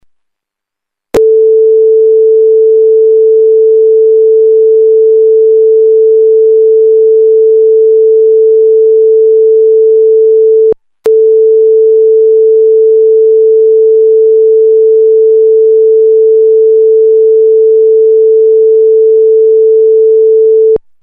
zobaczcie sami jak idealnie wygląda odtworzony sygnał wzorcowy - sinusoida 8kHz przez taki playerek z udziałem tego scalaka!!!
sinus_tda1543.mp3